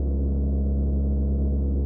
engine-low.ogg